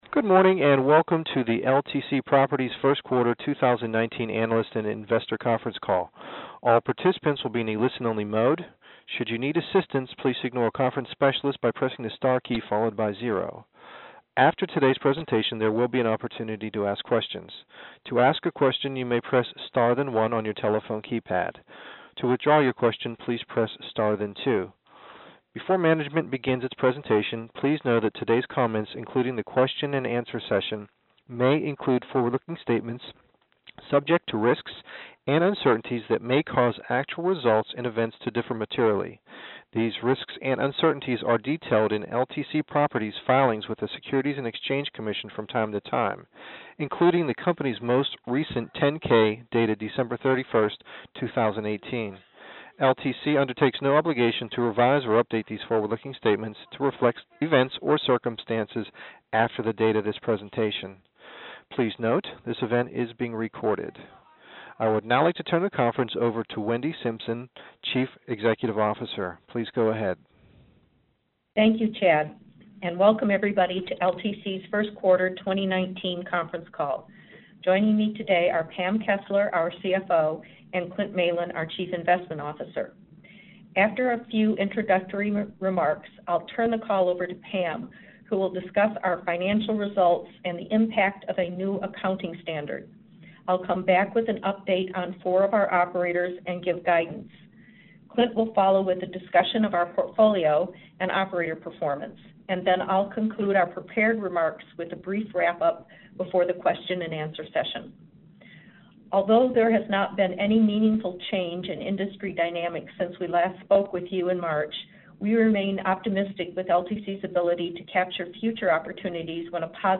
Earnings Webcast Q1 2019 Audio
Audio-Replay-of-LTC-Properties-Inc-Q1-2019-Earnings-Call.mp3